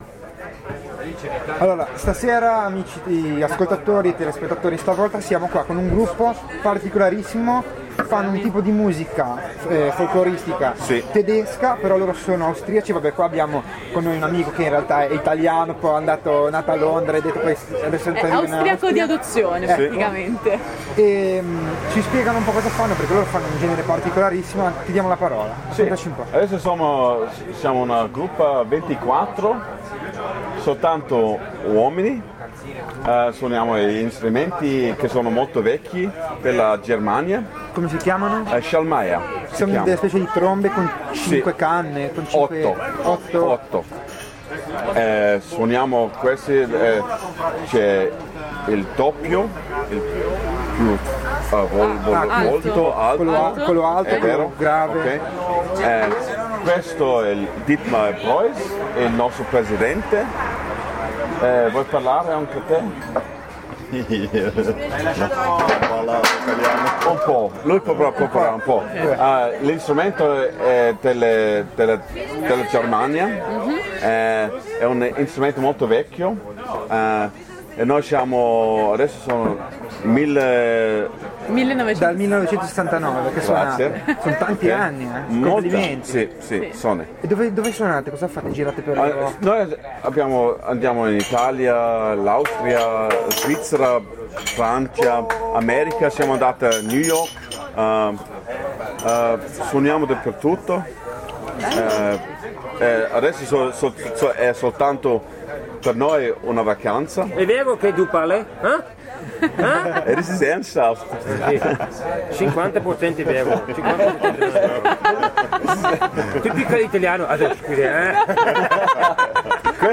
Intervista a Schalmeinen Rankweil